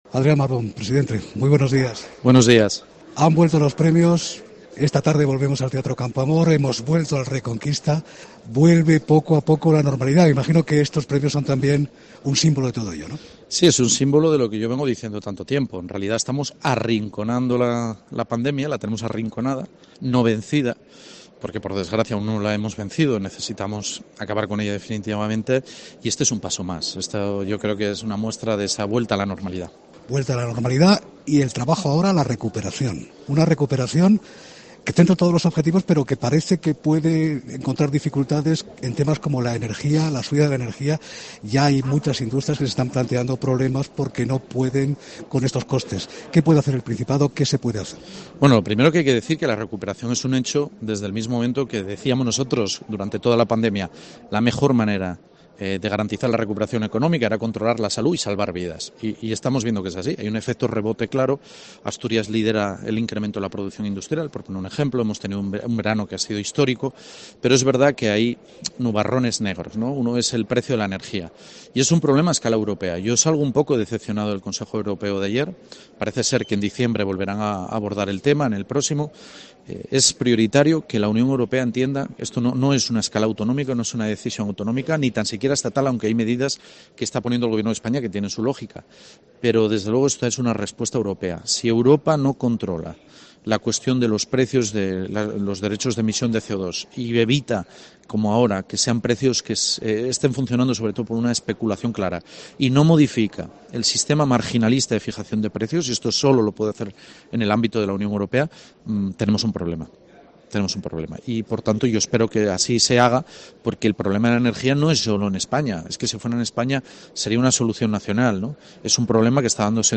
El presidente del Principado ha estado, en el Reconquista, en Herrera en COPE Asturias: "Estos Premios son un paso más, un símbolo de que estamos arrinconando a la pandemia"
Entrevista al presidente del Principado, Adrián Barbón, en Herrera en COPE Asturias